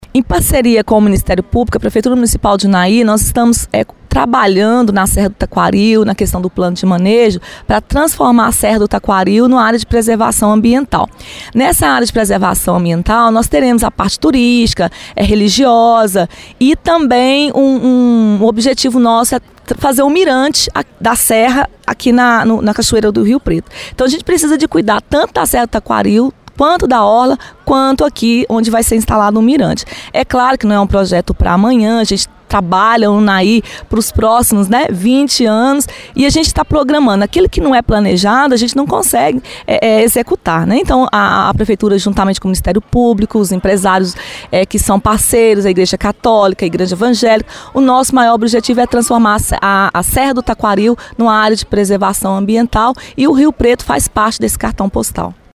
Catia Regina revelou ainda, que a prefeitura tem estudos em andamento com o objetivo elaborar um projeto para à construção de um “mirante” na Serra do Taquaril, com vistas para a Cachoeira do Rio Preto.